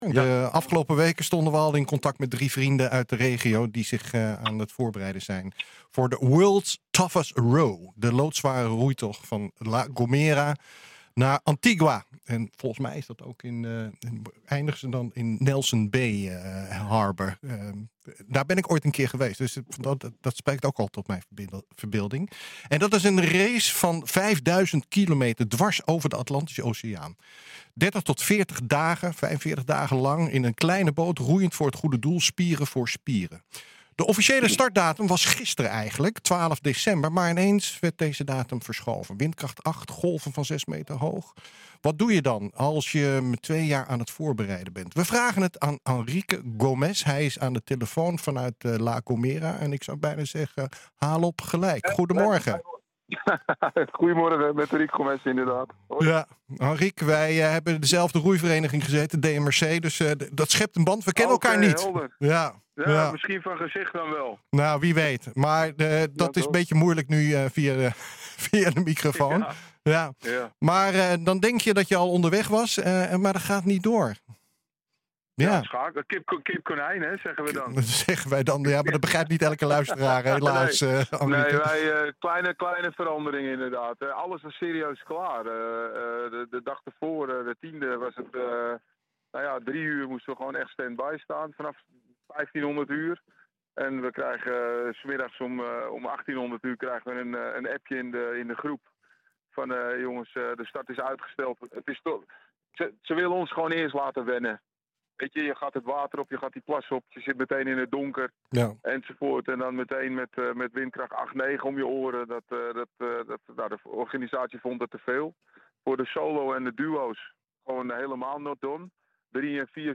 hij is aan de telefoon vanuit La Gomera.